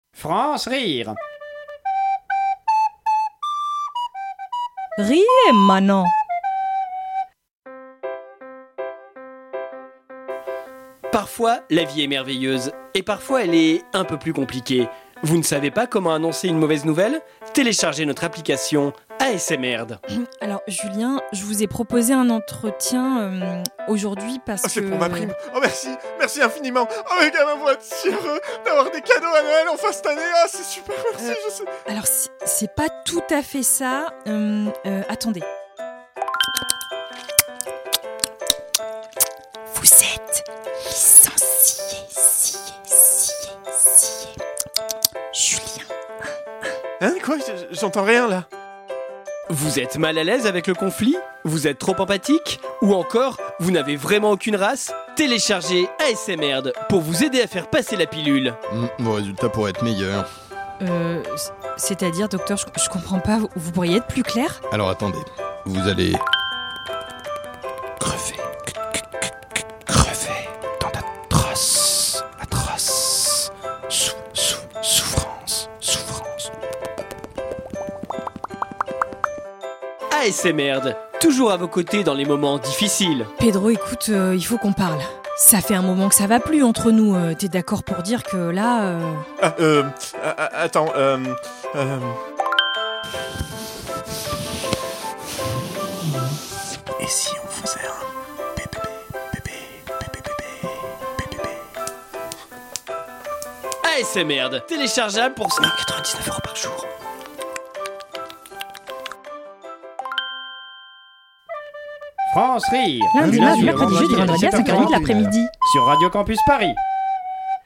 Création sonore
Ça va mieux en le chuchotant.